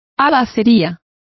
Complete with pronunciation of the translation of grocery.